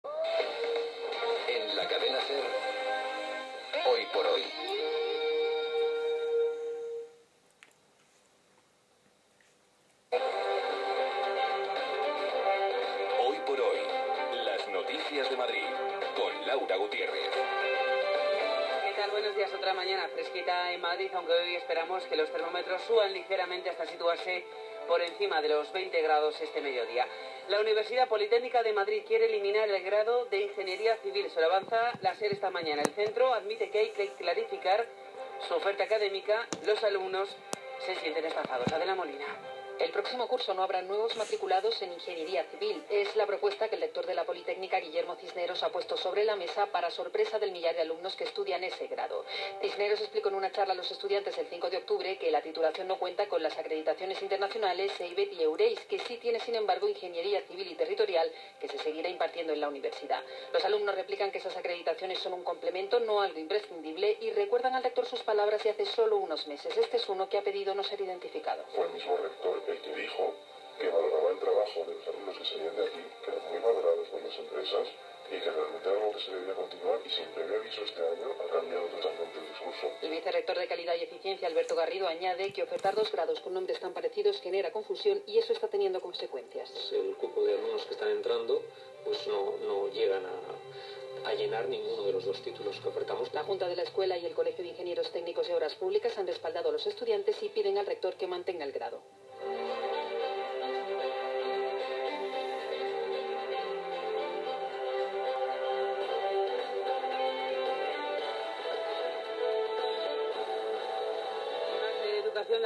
Cadena Ser ( audio de radio) y web han dado difusión a esta grave situación.